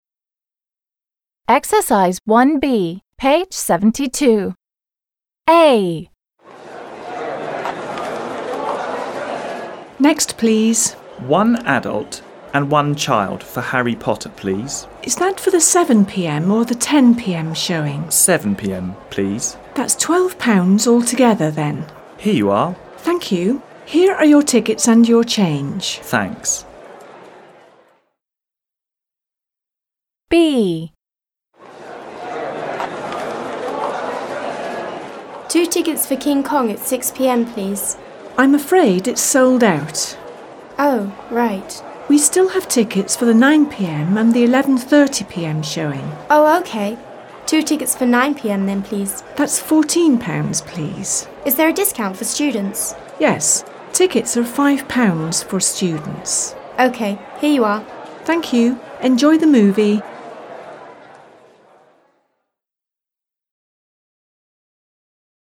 1. b) The sentences are from dialogues between a ticket seller and a customer.